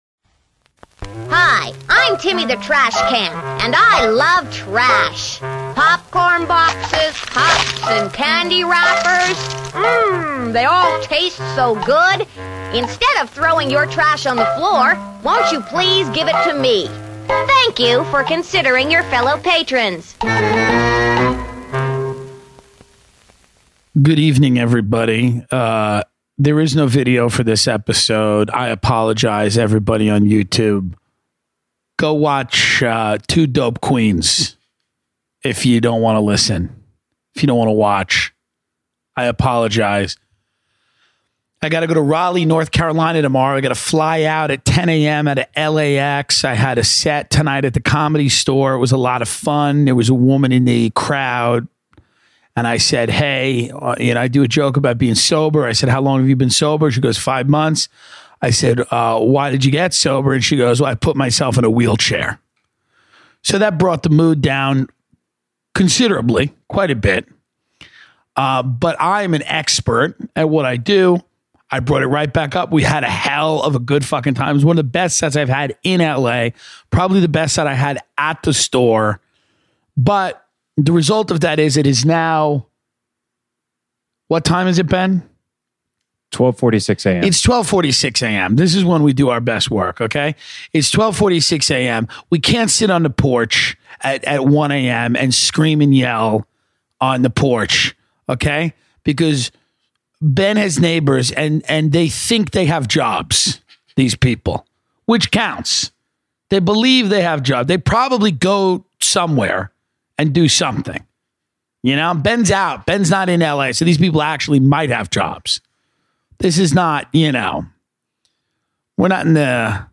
This week Tim broadcasts from the garage at 2 in the morning to talk about Songland